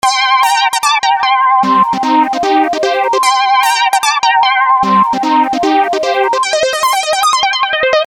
An instrumental recording.